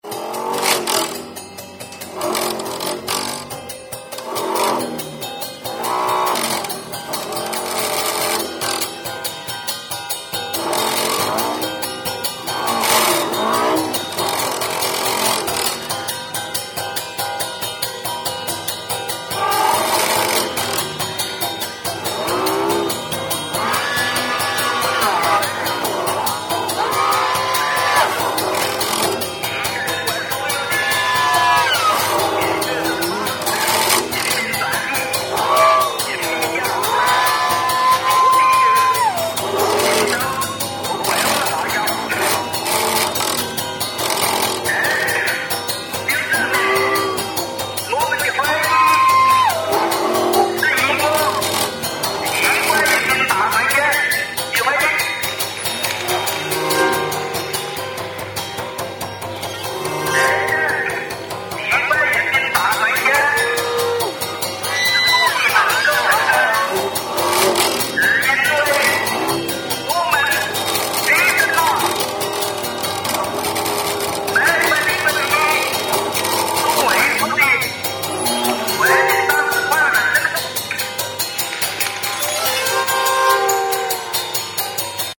19 string cello